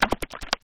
Closed Hats
07_Perc_12_SP.wav